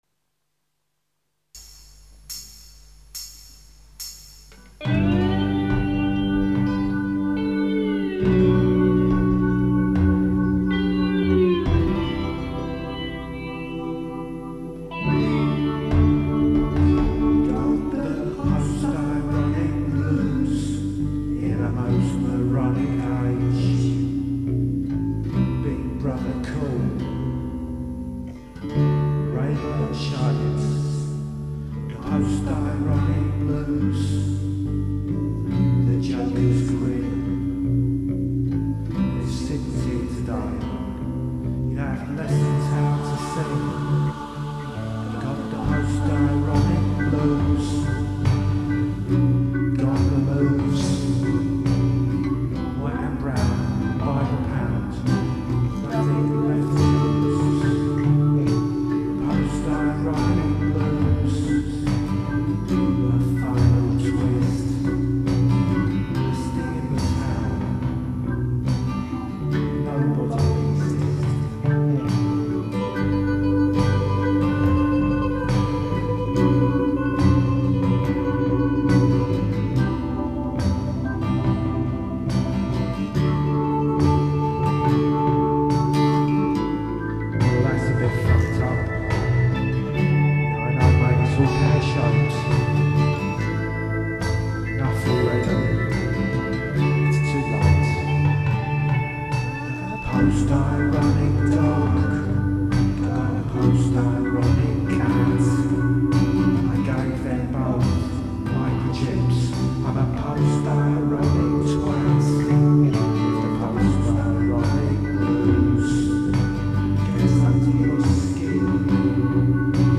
raw electric accoustic blues
Raw-songs
Folk